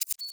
Coins (15).wav